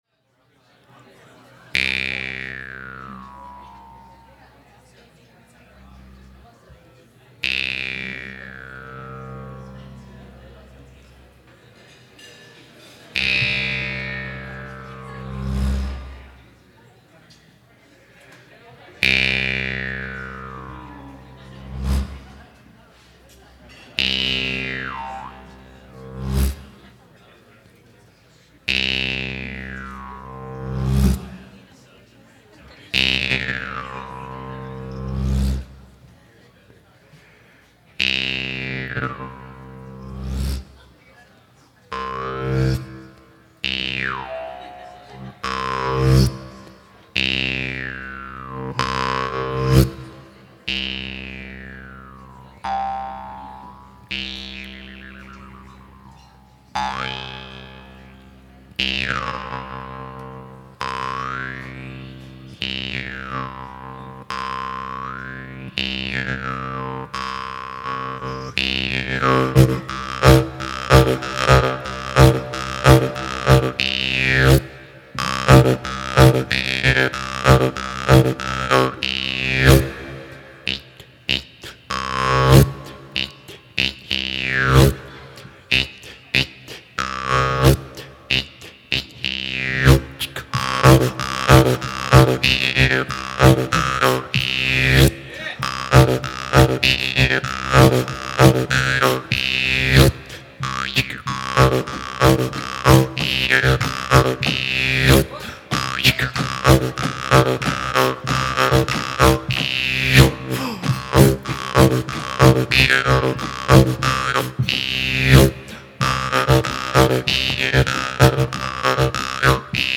Live at The Black Door 9/15/06
rollicking solo jaw harp piece